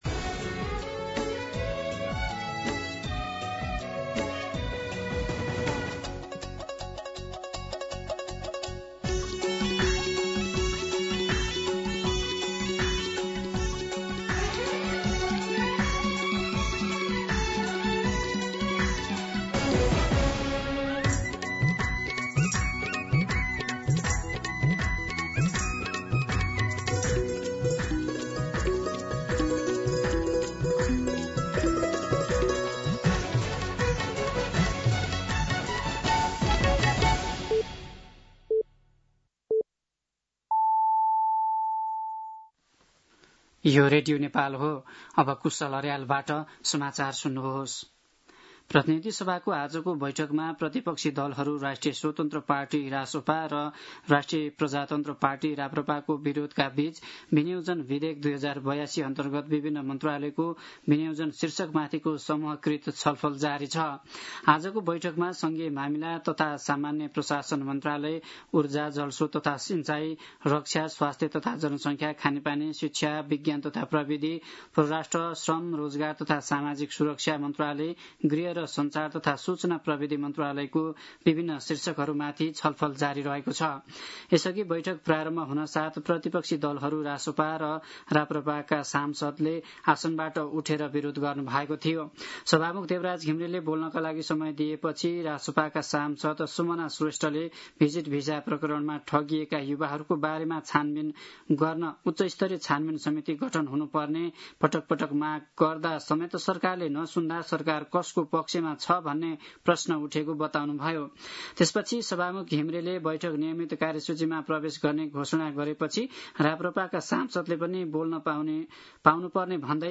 दिउँसो ४ बजेको नेपाली समाचार : ७ असार , २०८२
4-pm-Nepali-News-2.mp3